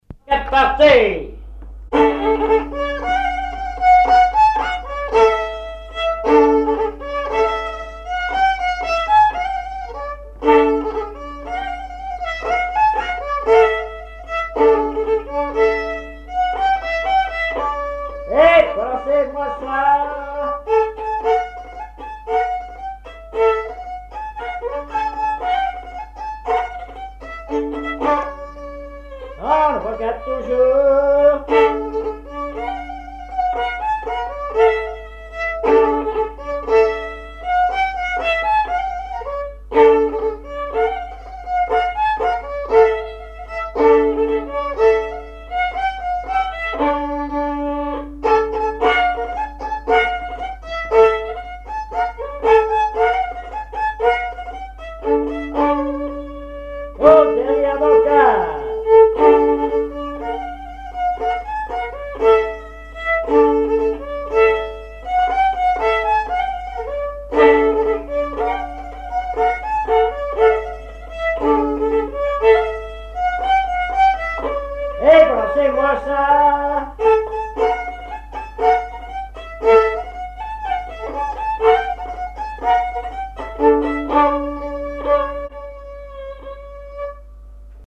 danse : avant-quatre
recherche de répertoire de violon
Pièce musicale inédite